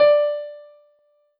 piano-ff-54.wav